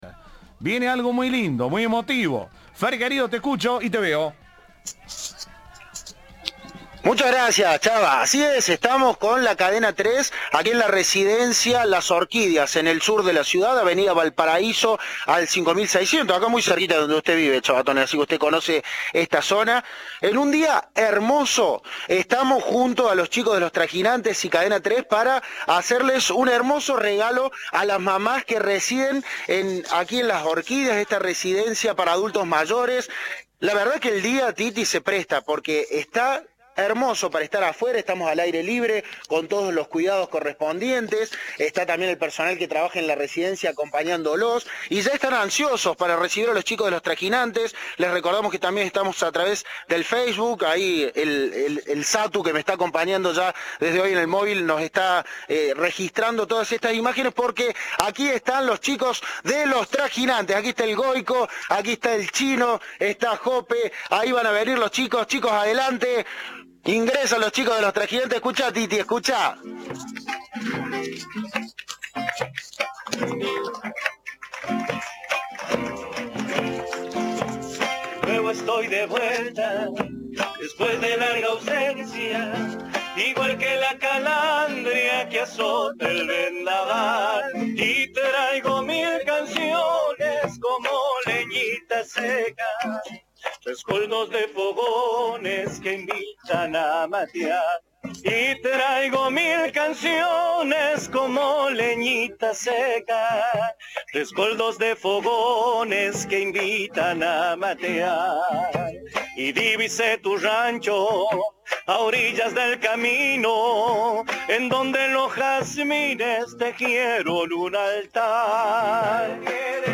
El grupo de folclore visitó junto a Cadena 3 un geriátrico ubicado en Villa Eucarística y le dedicaron canciones a aquellas que celebran su fecha este domingo.